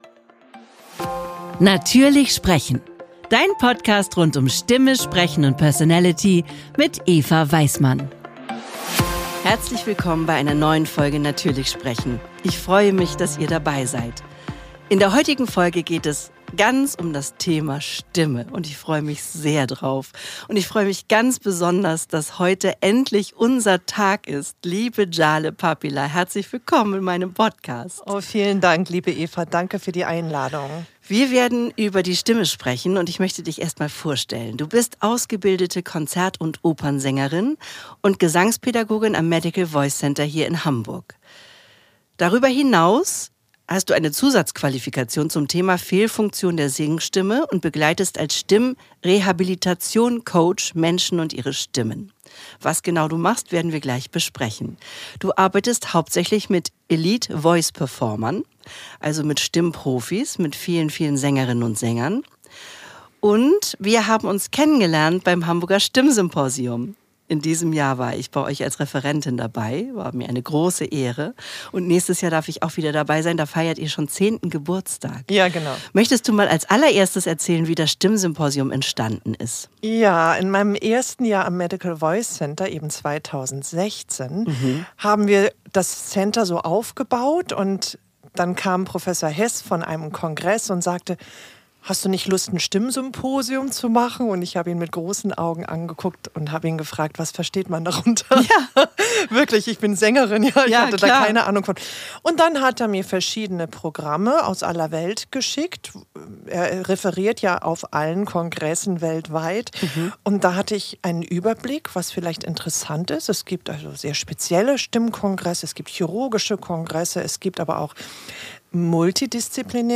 Beschreibung vor 4 Monaten Auf dieses Gespräch habe ich mich schon lange gefreut!